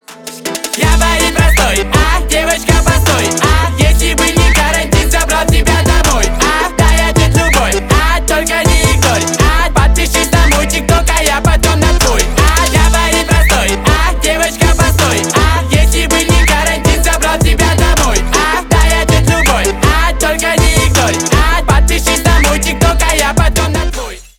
• Качество: 320 kbps, Stereo
Поп Музыка
громкие